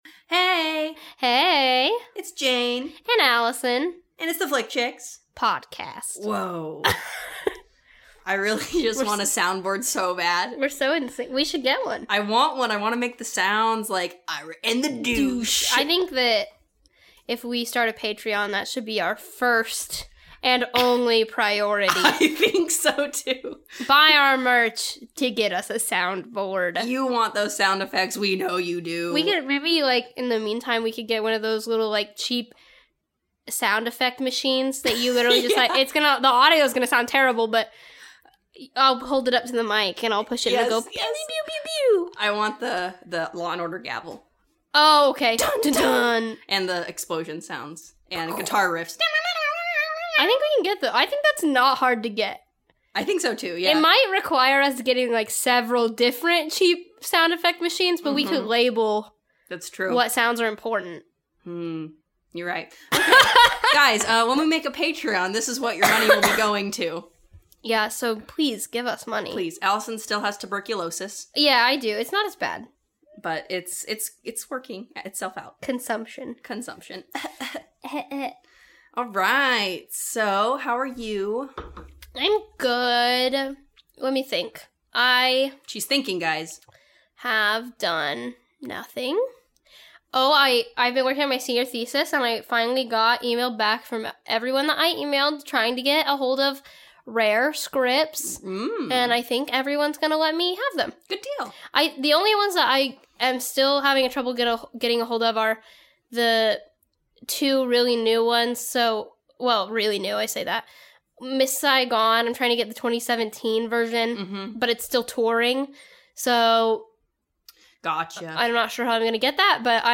Note: There is coughing through various points in the episode.